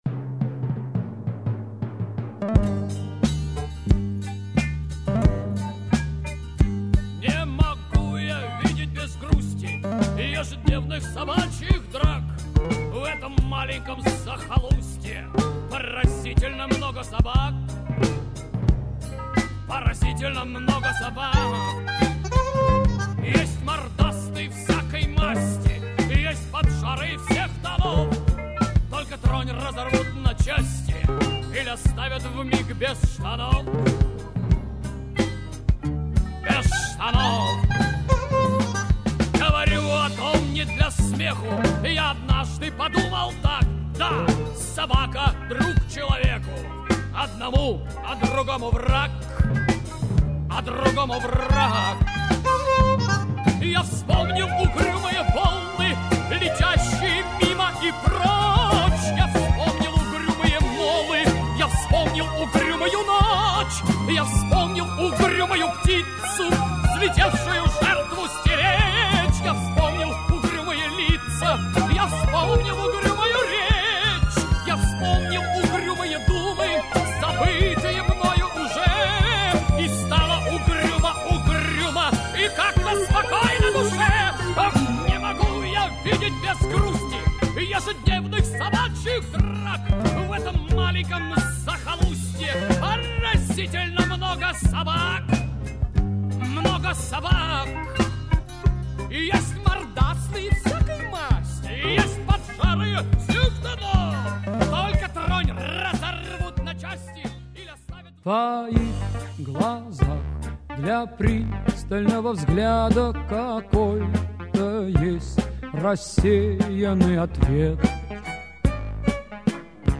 A kind of rock opera